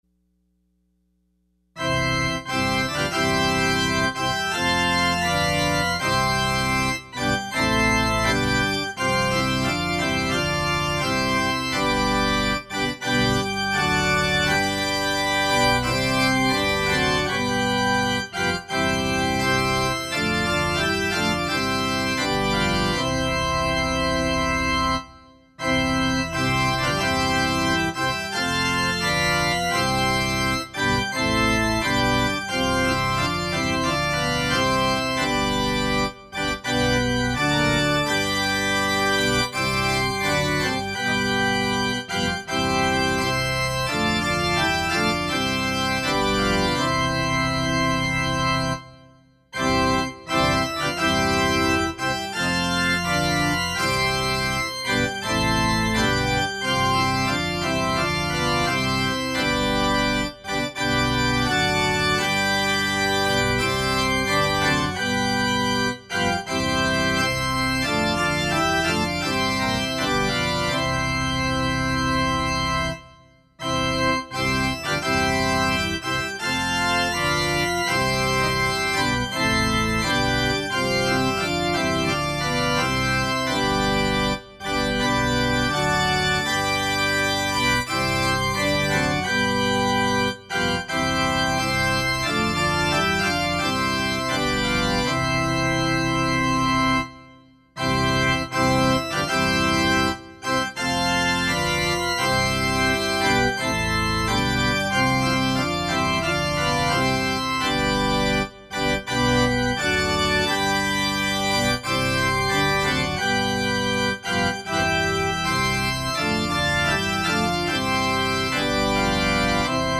Opening Hymn – Christ is Alive!